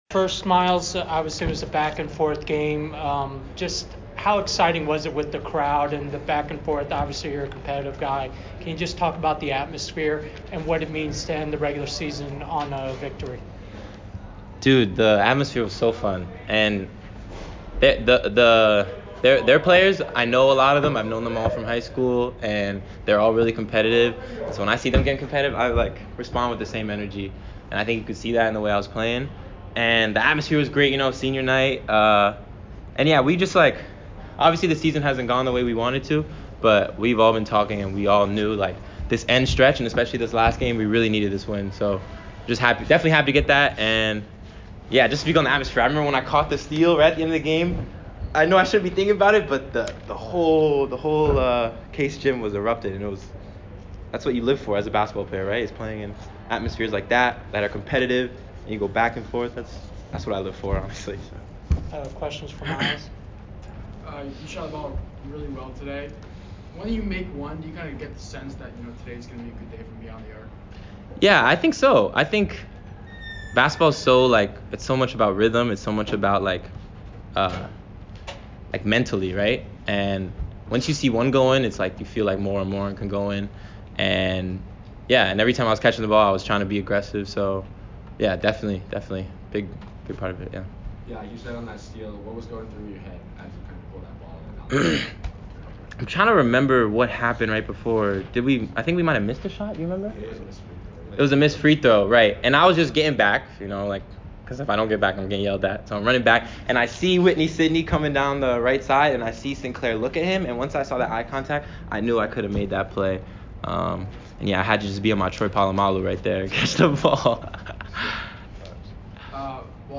Lehigh Postgame Interview